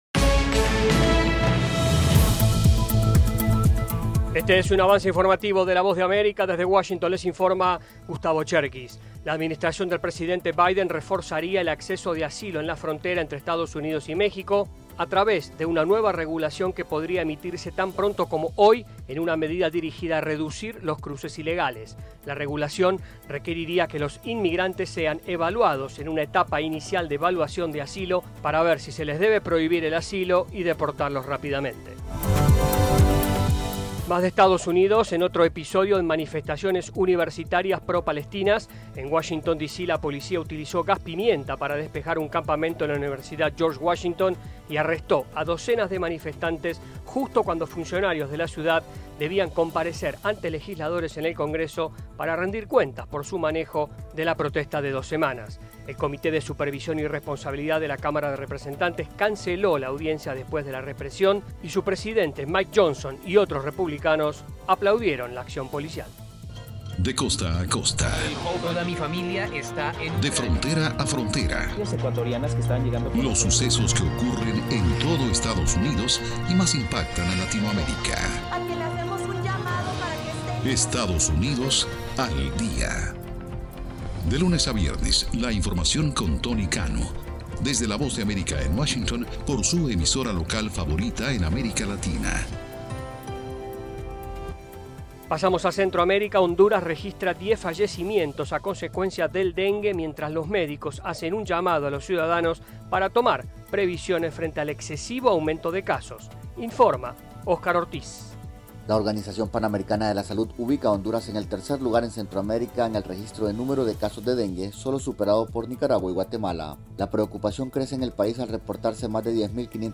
Avance Informativo